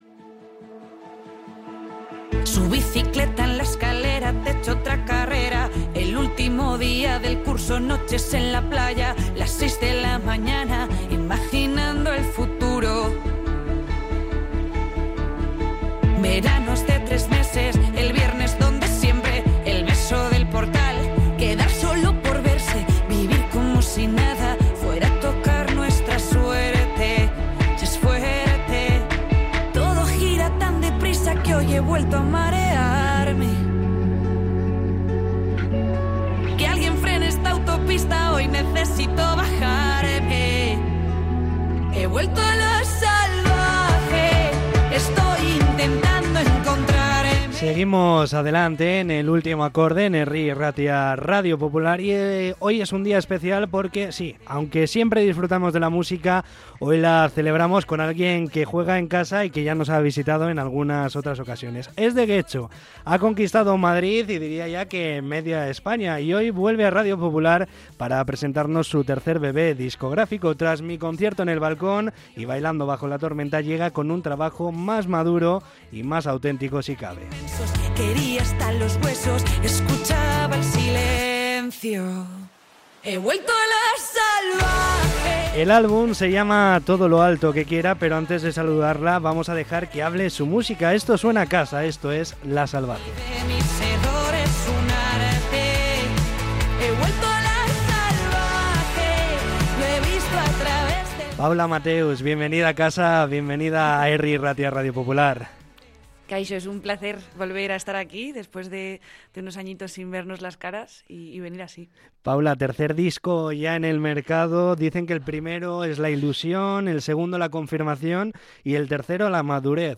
Entrevista con la cantautora getxotarra